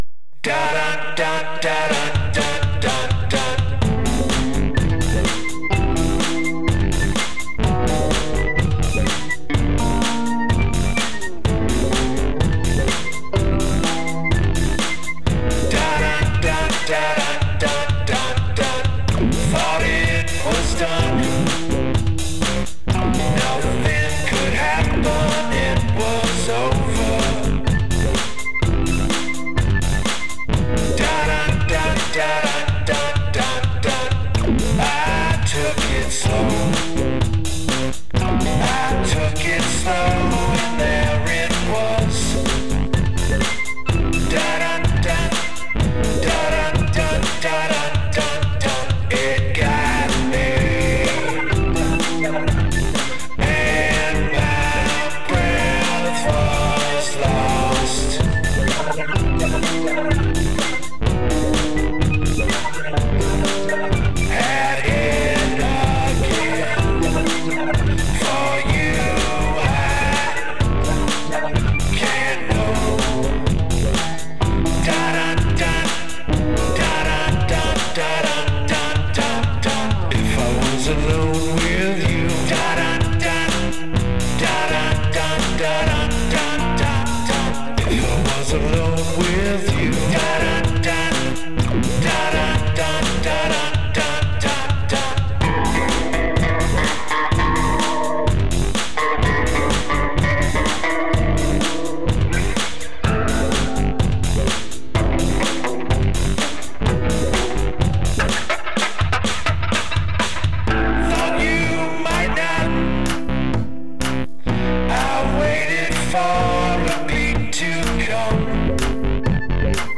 jittery pulse